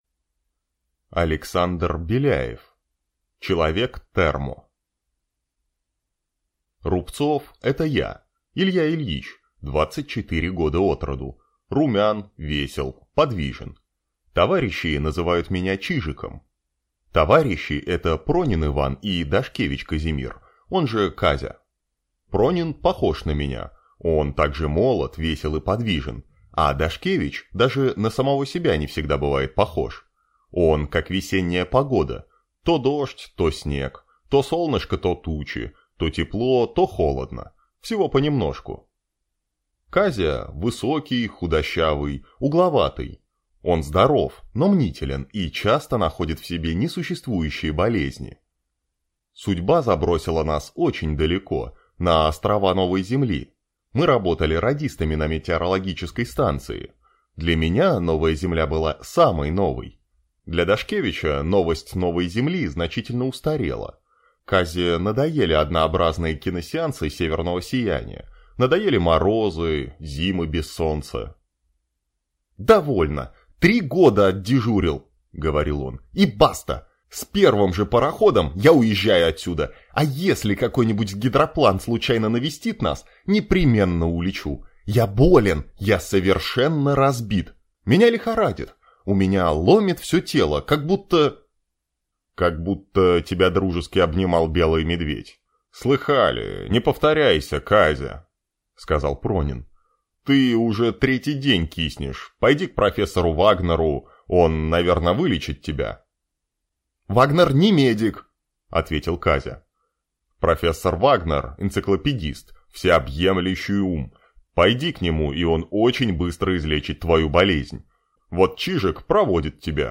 Аудиокнига Человек-термо | Библиотека аудиокниг